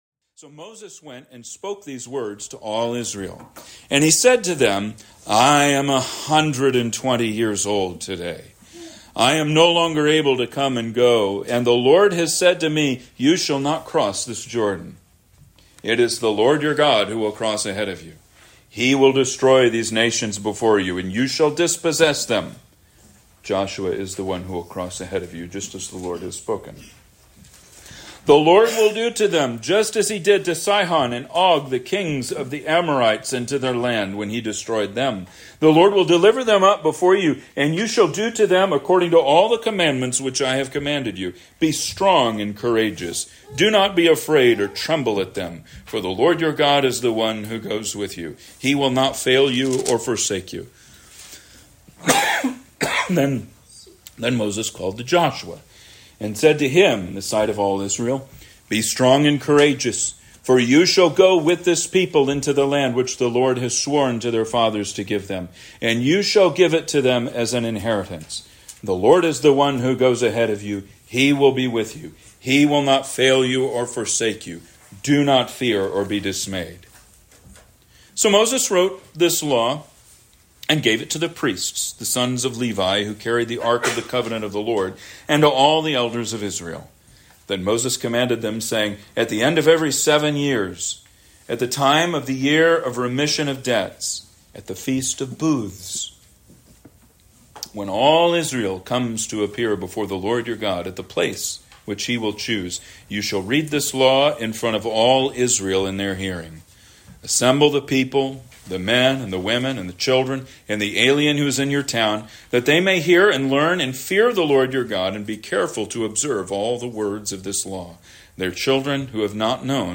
Sermons | Christ Church